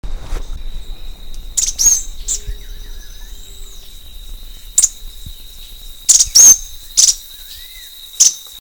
Cranioleucaobsoletaalarm.wav